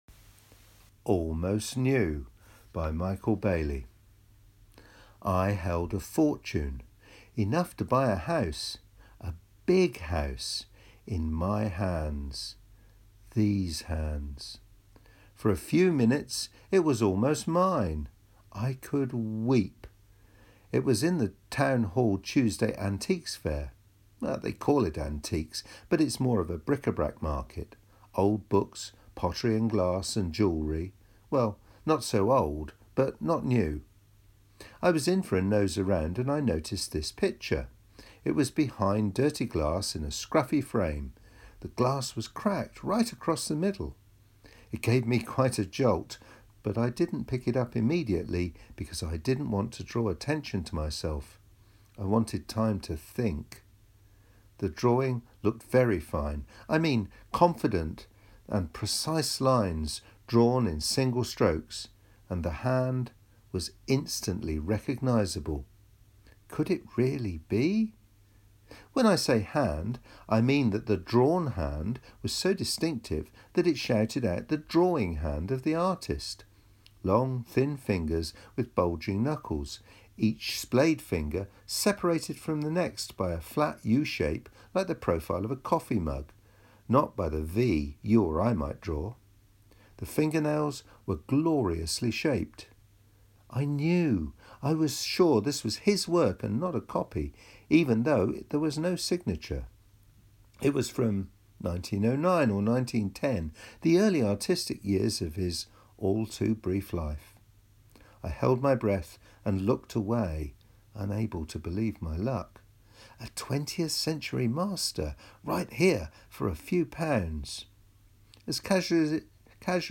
A short story